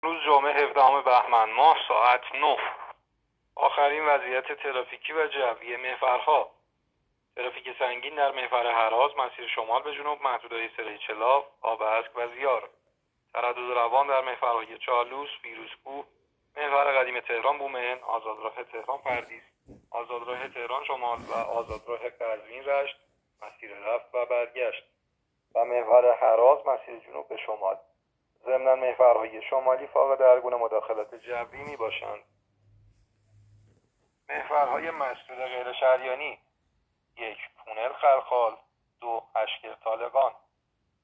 گزارش رادیو اینترنتی از آخرین وضعیت ترافیکی جاده‌ها ساعت ۹ هفدهم بهمن؛